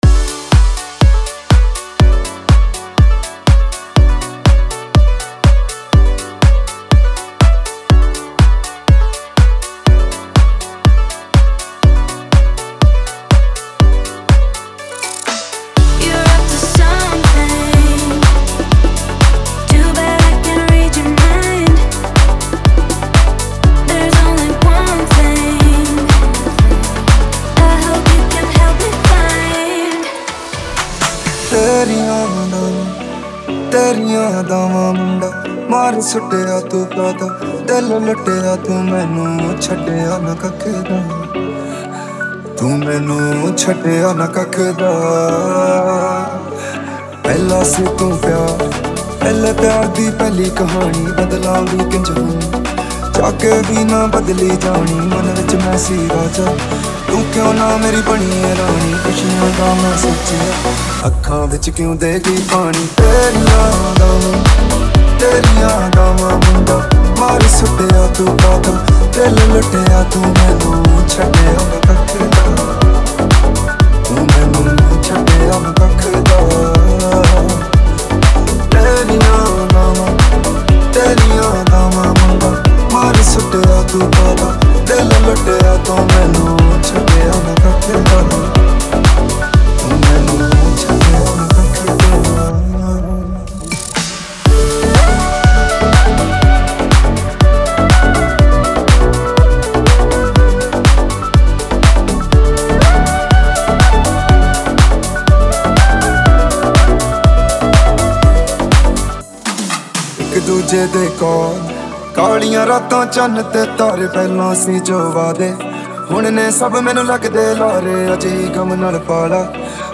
Bollywood Deep House
Punjabi DJ Remix Songs